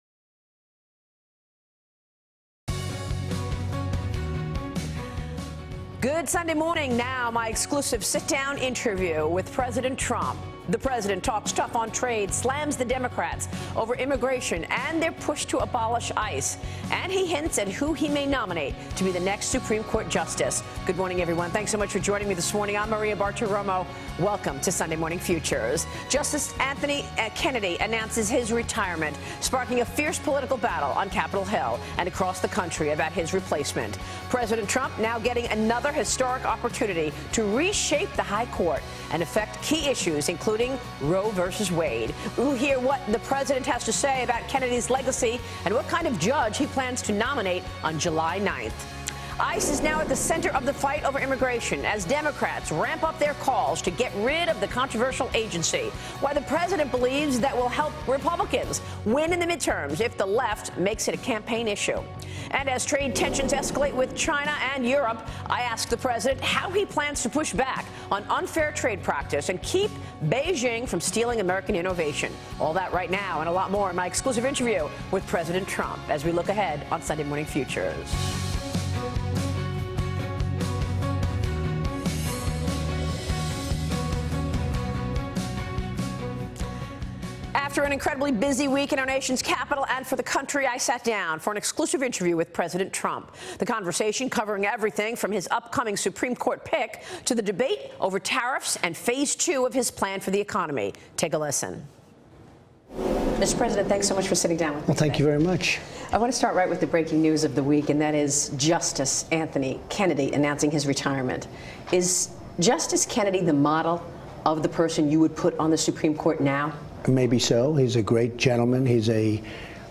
Fox News Maria Bartiromo interviews U.S. President Donald Trump